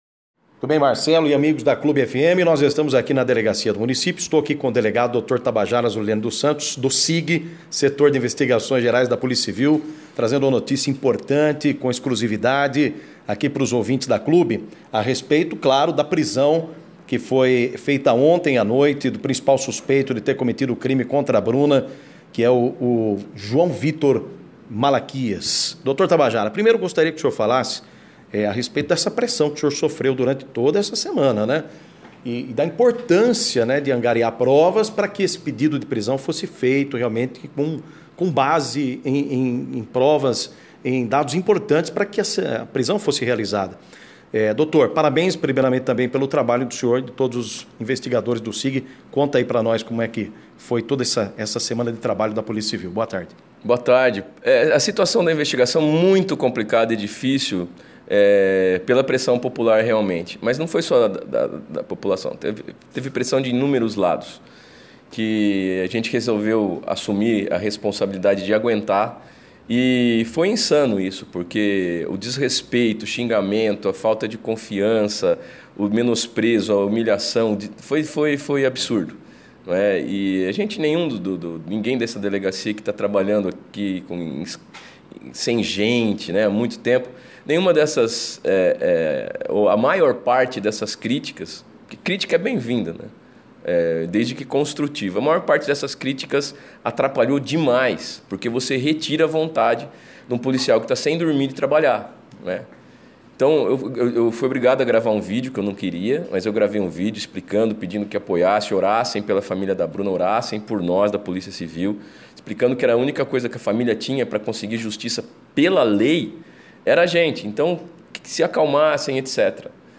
Arma apreendida e tiro no rosto Em entrevista